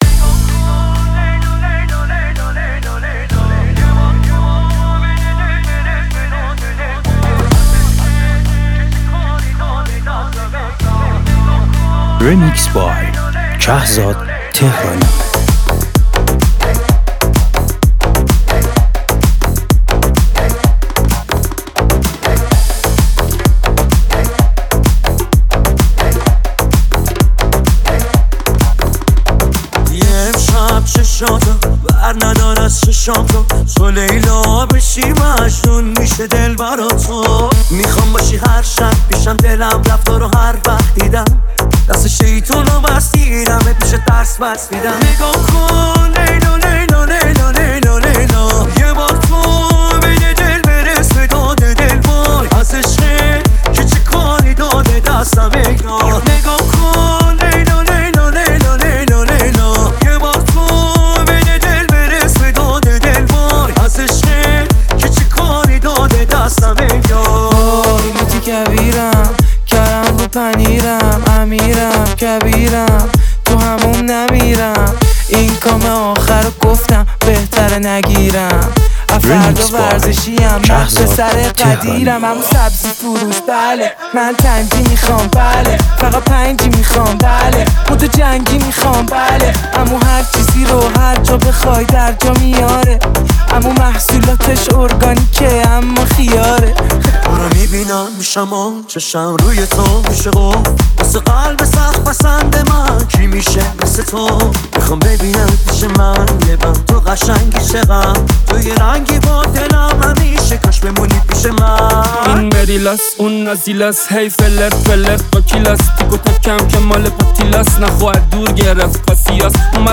این میکس ترکیبی خفن از آهنگ‌های رپ